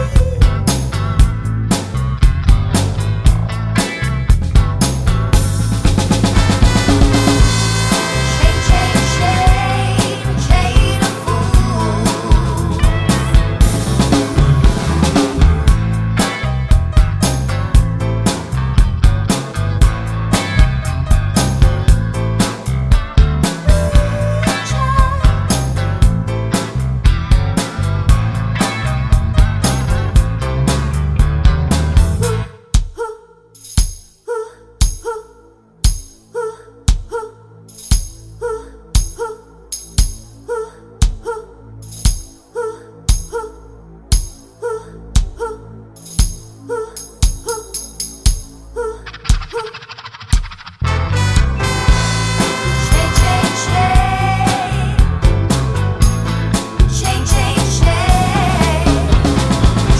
no saxes Soundtracks 2:57 Buy £1.50